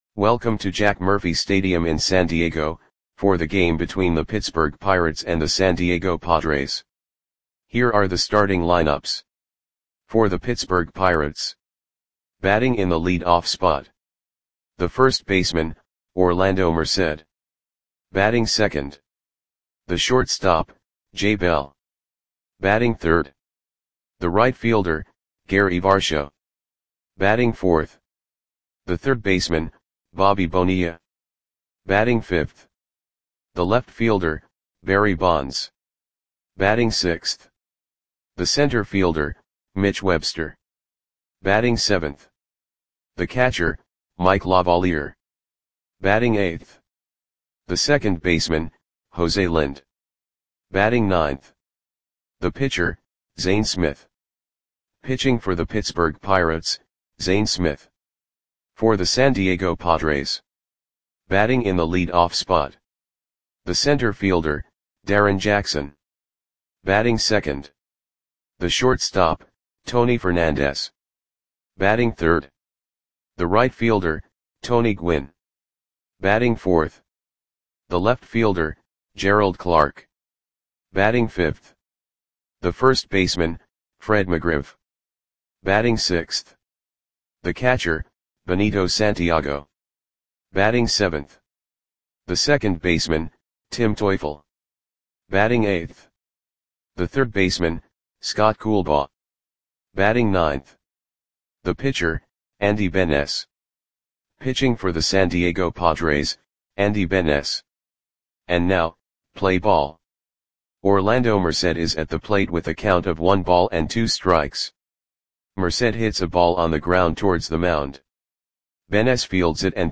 Audio Play-by-Play for San Diego Padres on June 19, 1991